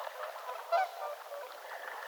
laulujoutsen ääni kuin torven törähdys
laulujoutsenen_torven_torahdys.mp3